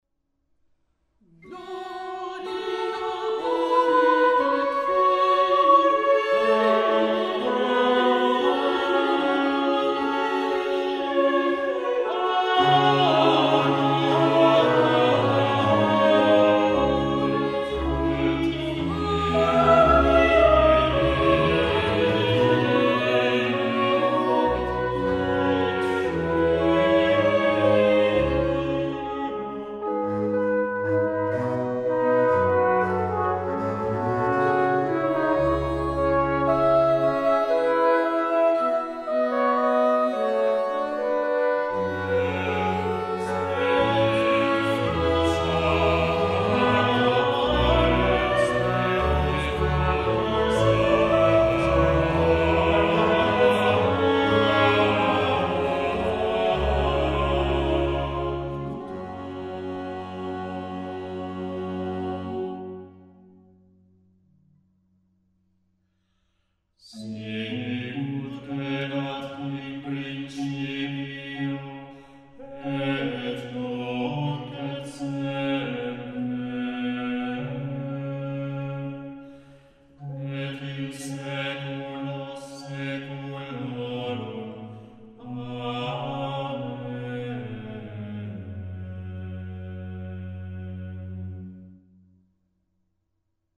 Audio - Església de Sant Joan de Sanata